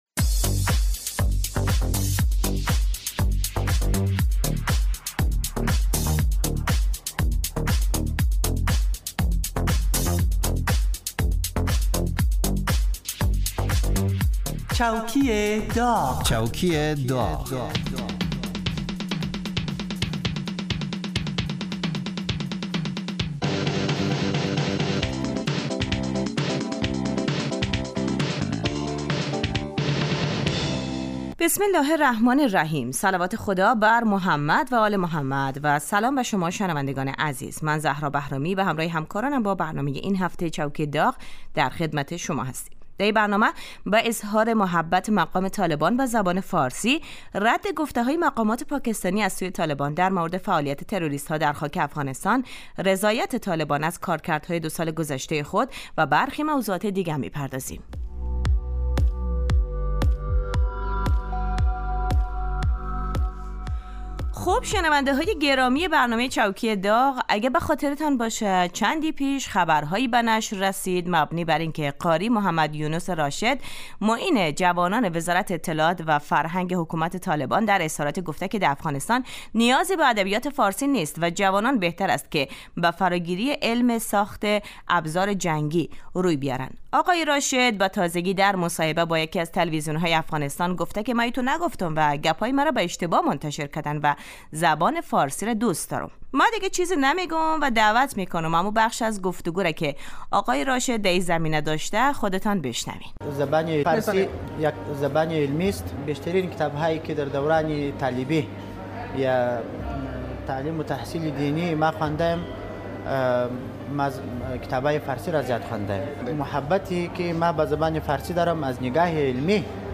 این برنامه با نیم نگاه طنز به مرور و بررسی اخبار و رویدادهای مهم مربوط به دولت حاکم در افغانستان می پردازد.